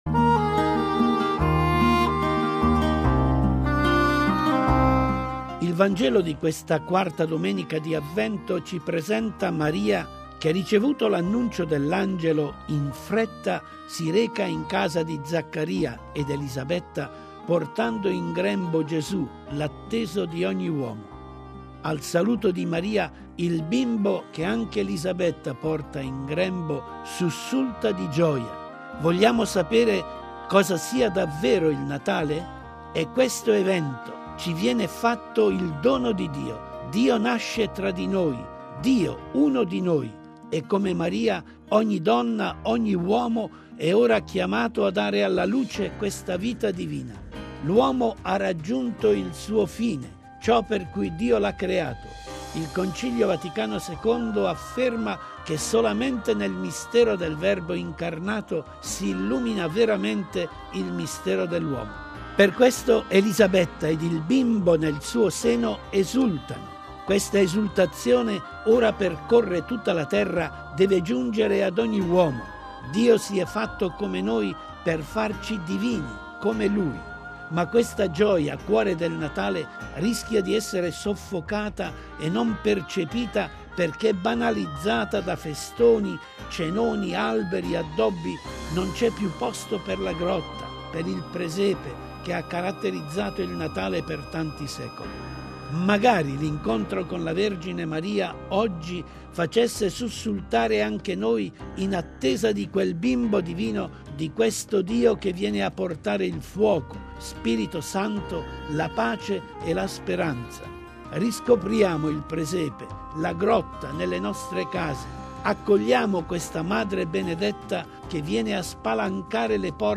Il commento